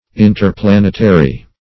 Interplanetary \In`ter*plan"et*a*ry\, a.